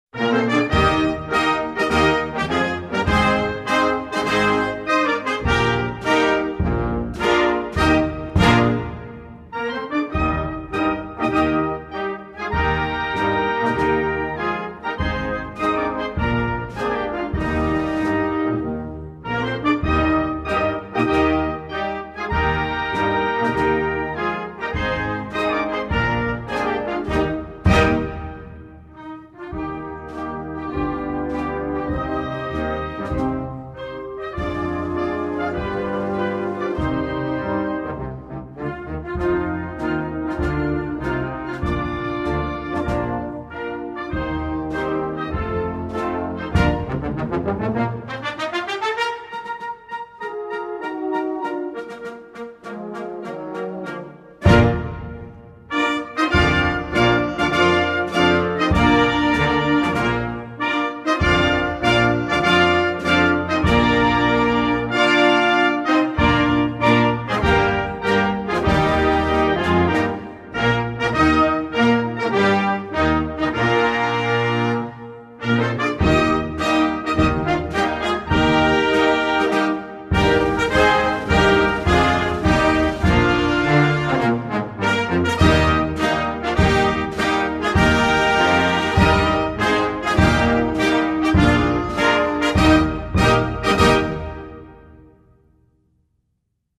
Dominican_Republic_National_Anthem.mp3